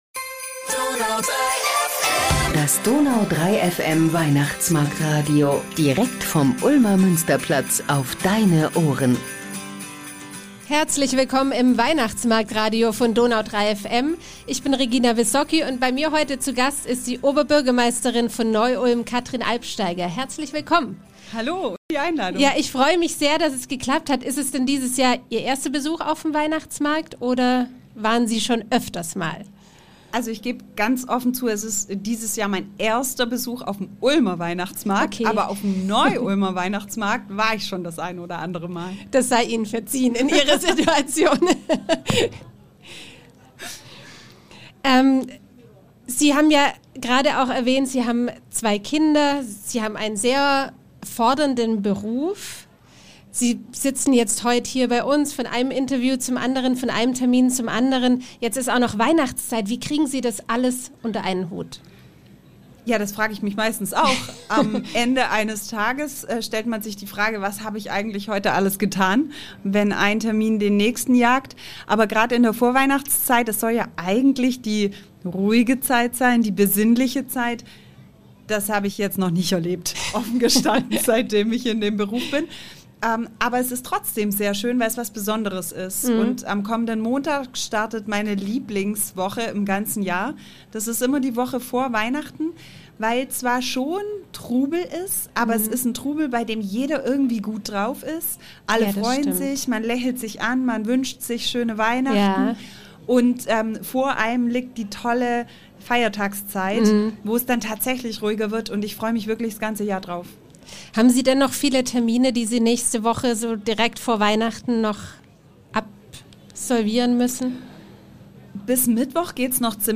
Die Neu-Ulmer Oberbürgermeisterin Katrin Albsteiger zu Gast im DONAU 3 FM Weihnachtsmarktradio ~ Ulmer Weihnachtsmarkt-Podcast Podcast
Was ist 2024 in Neu-Ulm geplant? Wie feiert sie Weihnachten und was sind ihre Vorsätze für das Neue Jahr? Das hat Neu-Ulms Oberbürgermeisterin Katrin Albsteiger uns im Weihnachtsmarkt-Radio erzählt.